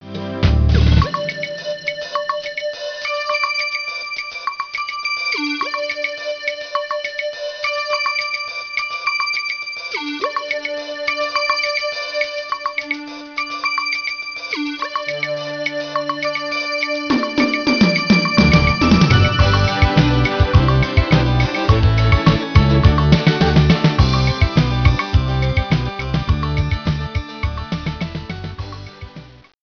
unique atmospheric sound